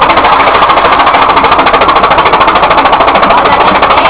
独特の音を響かせながら、ＳＬはぐんぐん進んでいきます。